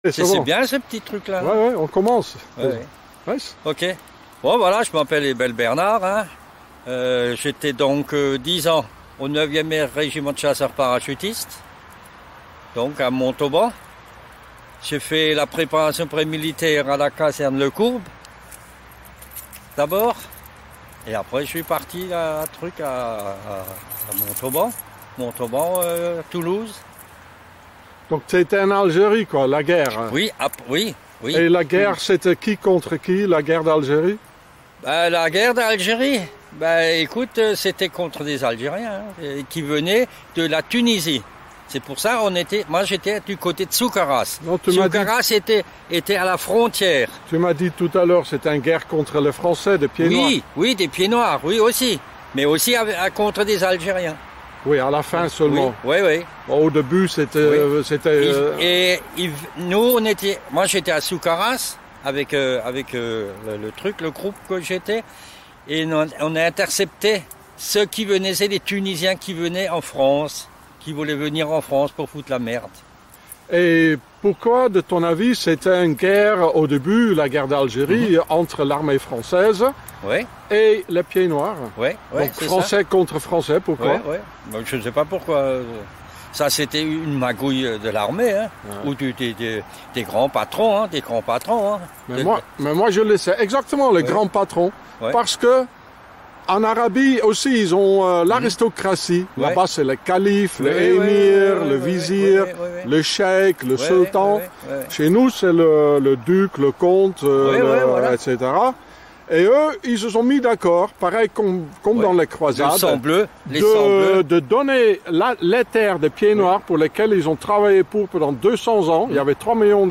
Parachutiste Alsacien parle de la Guerre d'Algérie - une Guerre entre des Français et des Français
French Paratrooper talks about the Algerian War, which was mainly a war between the French and the French.